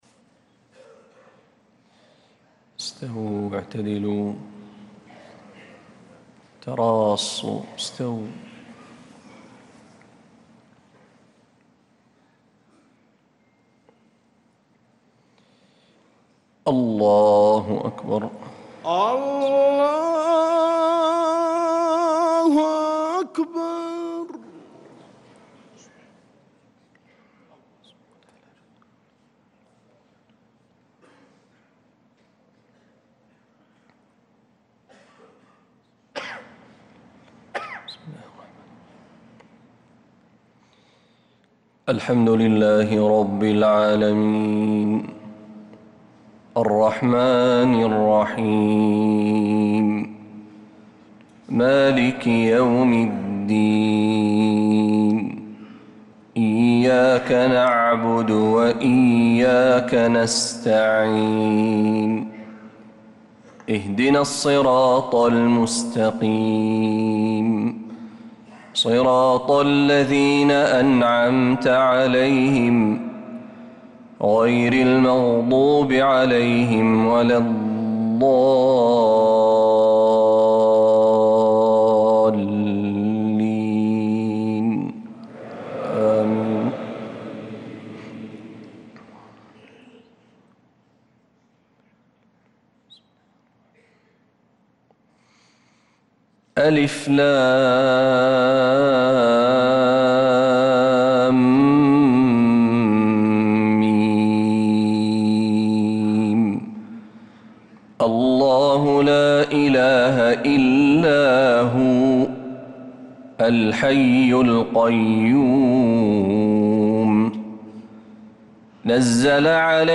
صلاة الفجر للقارئ محمد برهجي 24 ربيع الآخر 1446 هـ
تِلَاوَات الْحَرَمَيْن .